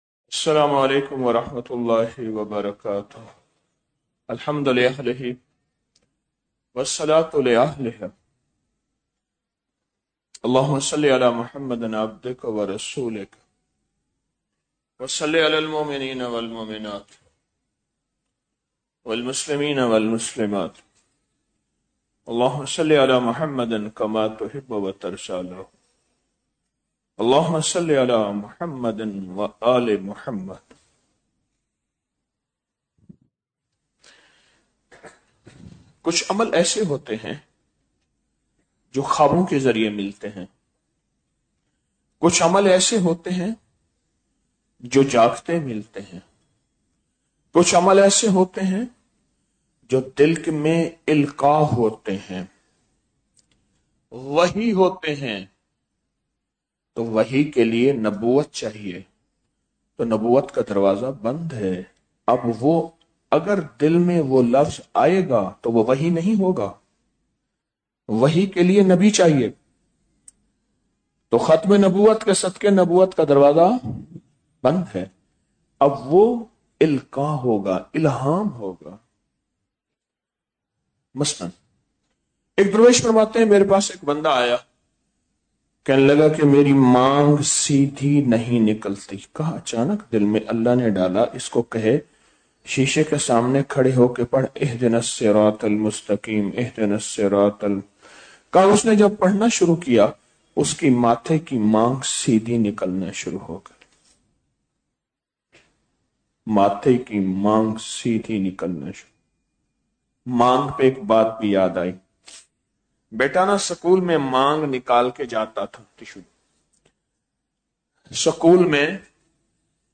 مقدر جگانے والے نوافل - 12 رمضان المبارک بعد نماز تراویح - 01 مارچ 2026ء